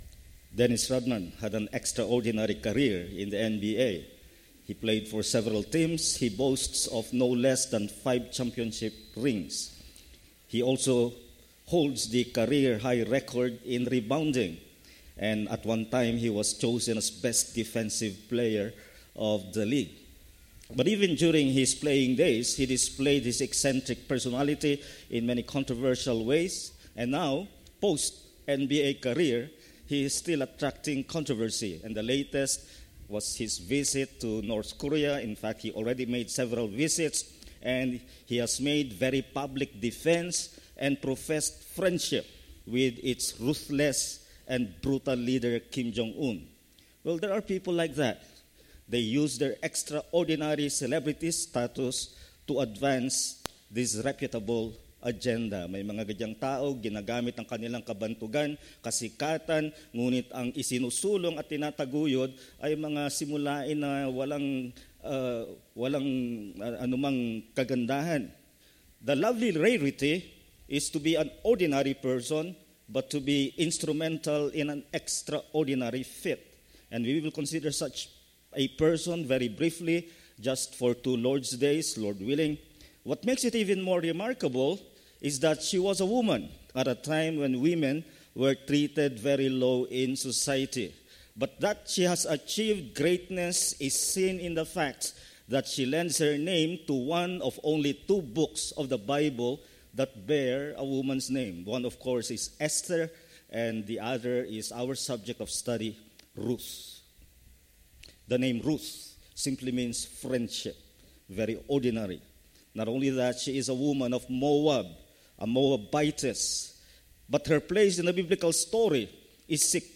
Check regularly for new sermons.